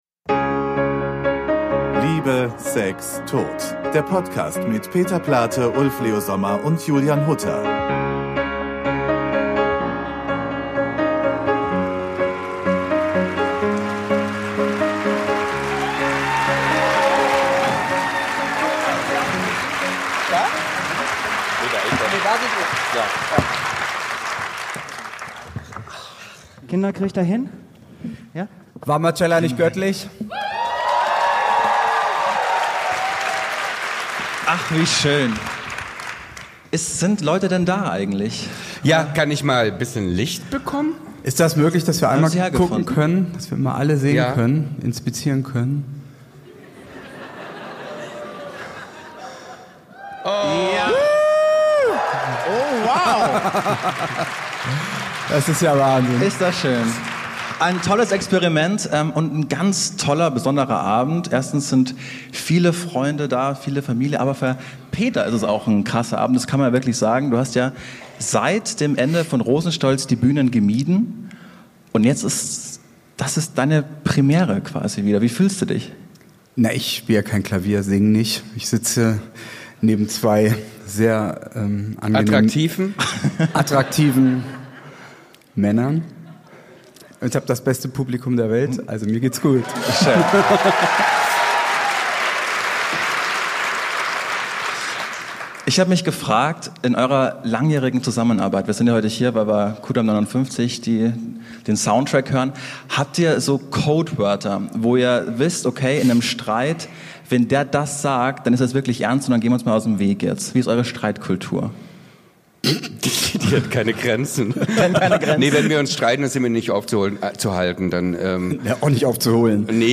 LIVE IM THEATER DES WESTENS ~ Liebe, Sex, Tod. Podcast
Beschreibung vor 2 Jahren Das gab es noch nie: Liebe, Sex, Tod - live und in Farbe. Vergangenen Montag haben wir unseren ersten live Podcast aufgezeichnet und sind immer noch hin und weg.